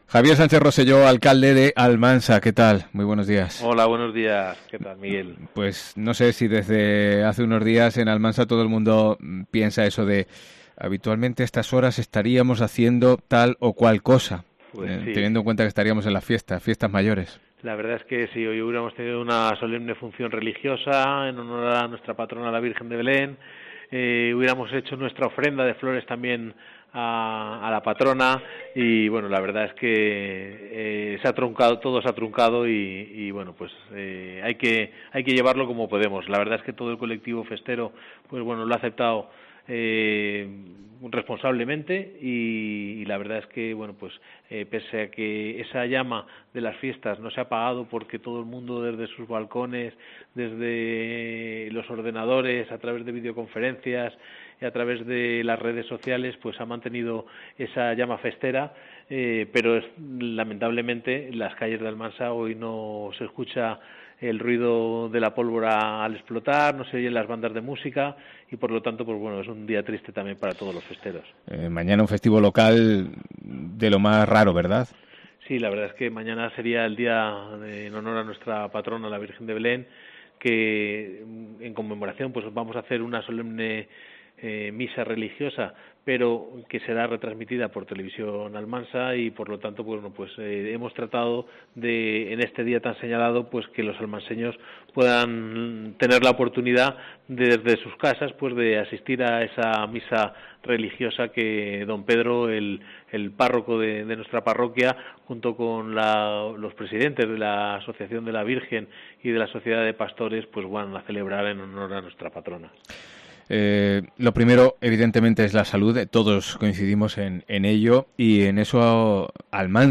El alcalde de Almansa, Javier Sánchez Roselló nos cuenta en COPE cómo han cambiado estos días que deberían ser de Fiestas Mayores y no de crisis sanitaria